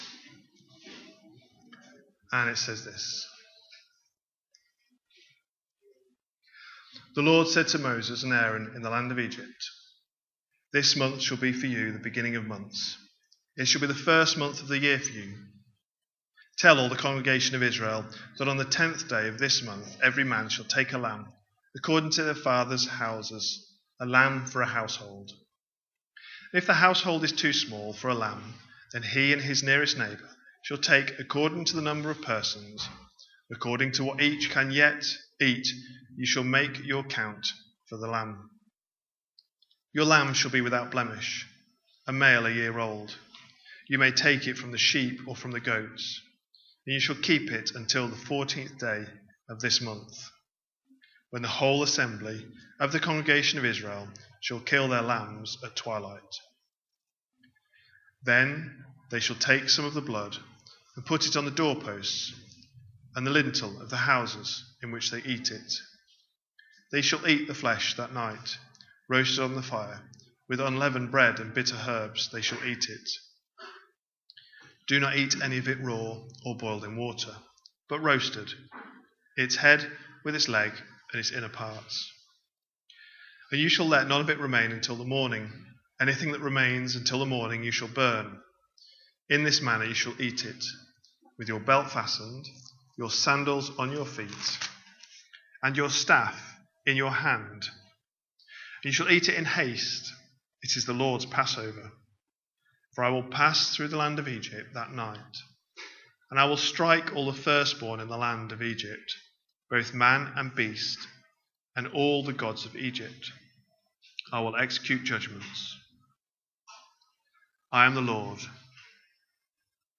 A sermon preached on 10th August, 2025, as part of our Exodus series.